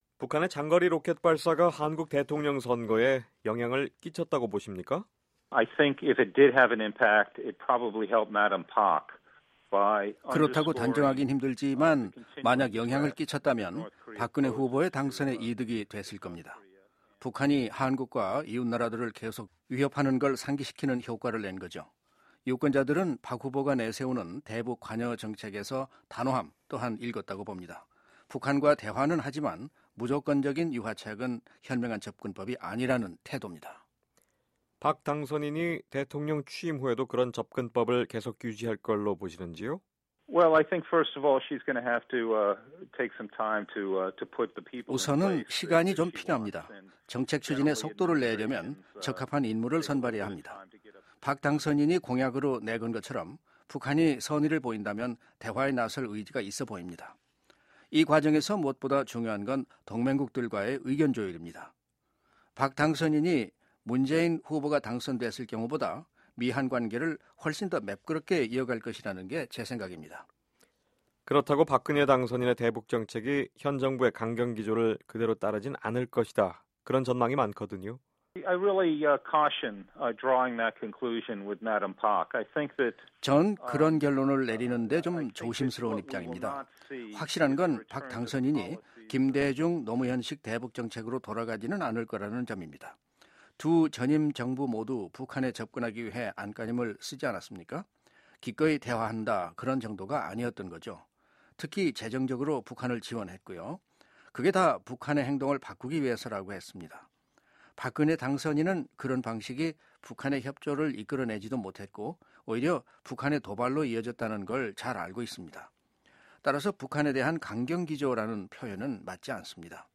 [인터뷰] 리스 전 국무부 정책실장 "박근혜, 단호한 대북정책 펼것"